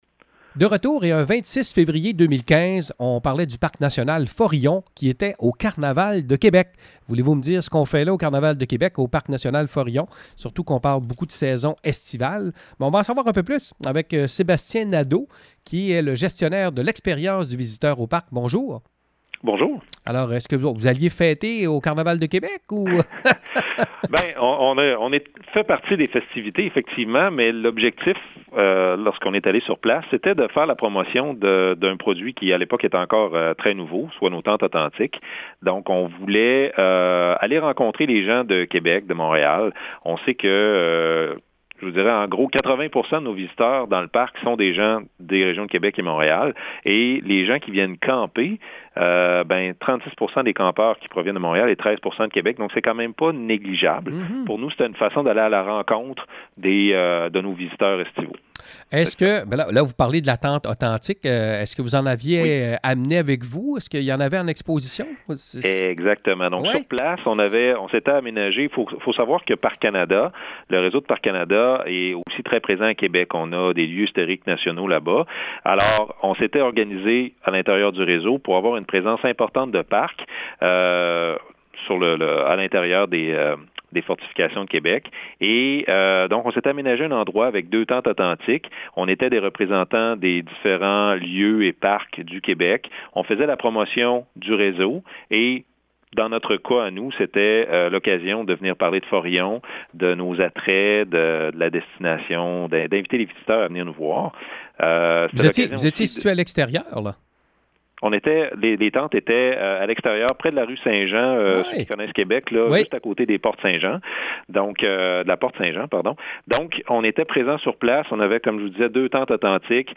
FORILLON-CARNAVAL.mp3